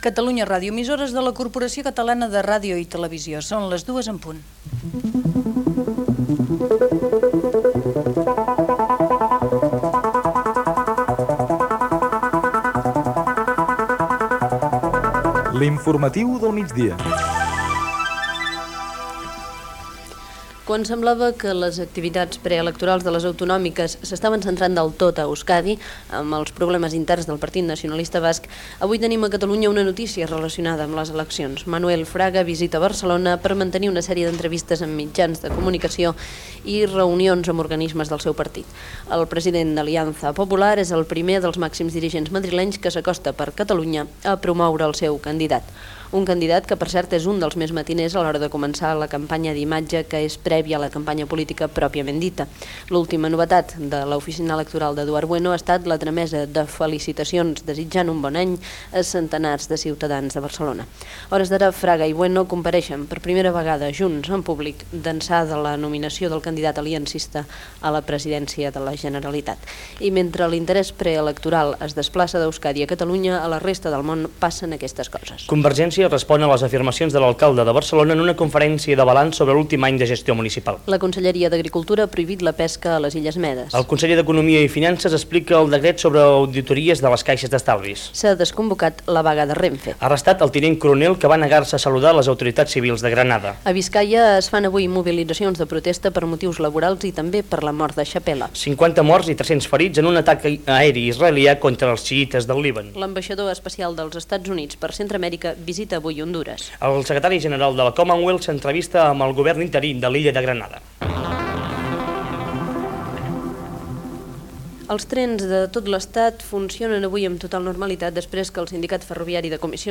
Identificació, hora, careta, visita de Manuel Fraga (pesident d'Alianza Popular) a Barcelona, titulars informatius, desconvocada la vaga de RENFE
Informatiu
FM